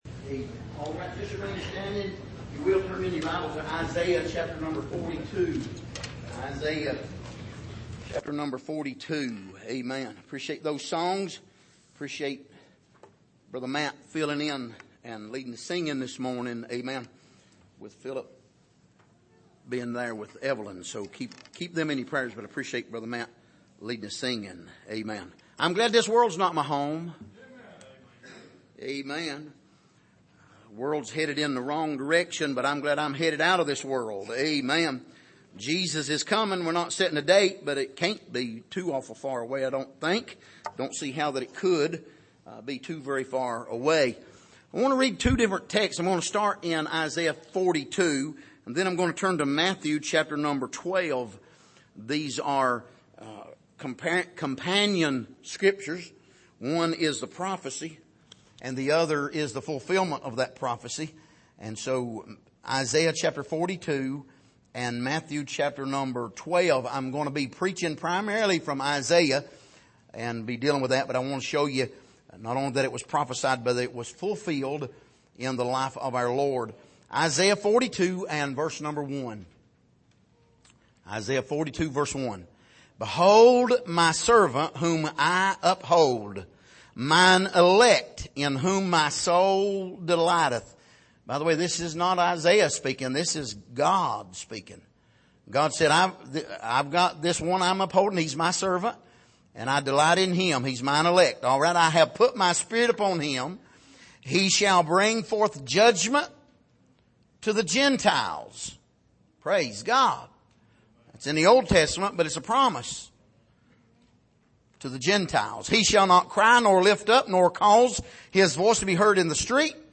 Passage: Isaiah 42:1-4, Matthew 12:17-21 Service: Sunday Morning